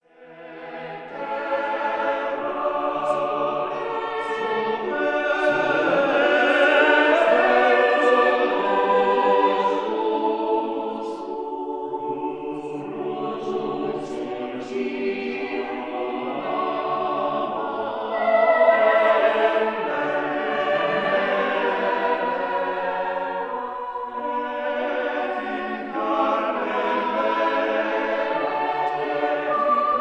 1954 recording made in the Brompton Oratory